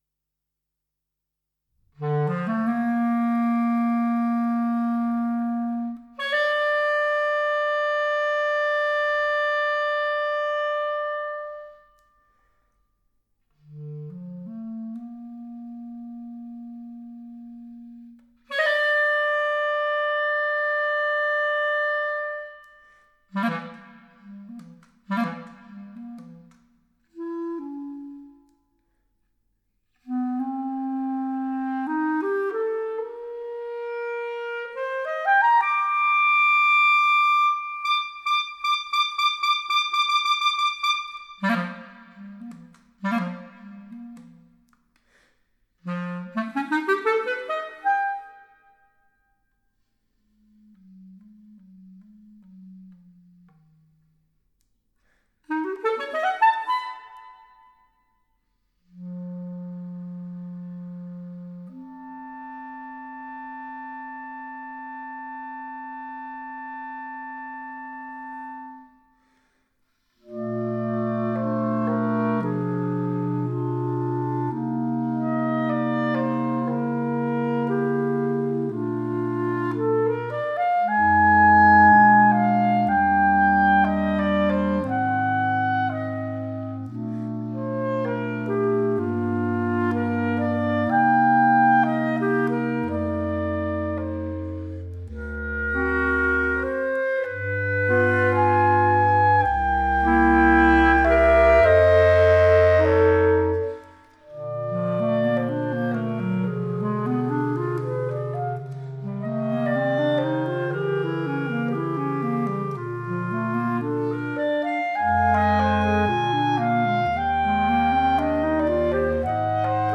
for clarinet quartet